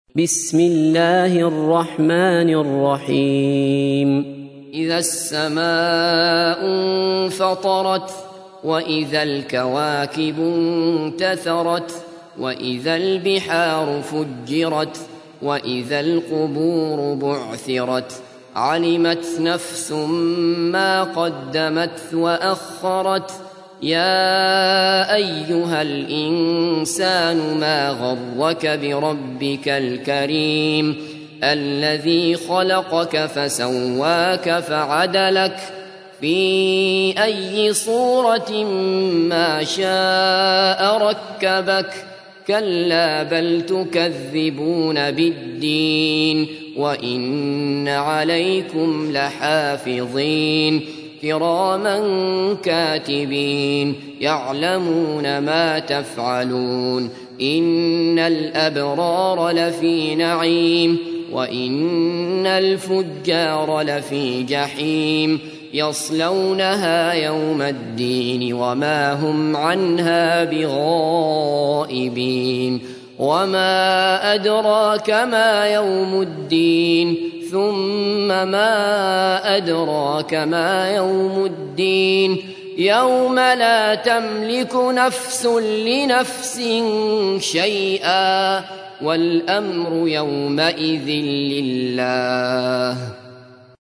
تحميل : 82. سورة الانفطار / القارئ عبد الله بصفر / القرآن الكريم / موقع يا حسين